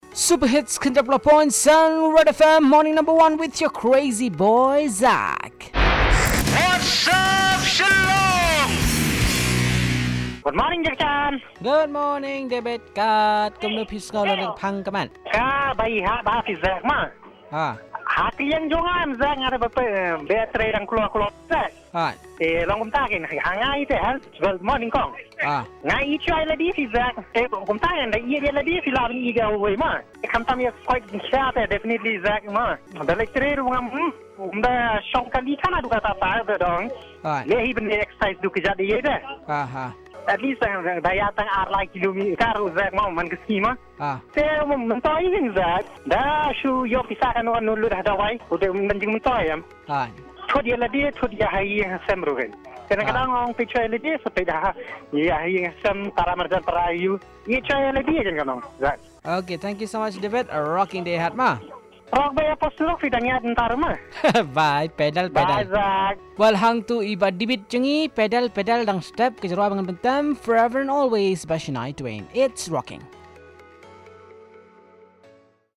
What’s Up Shillong Caller 1 on putting our health first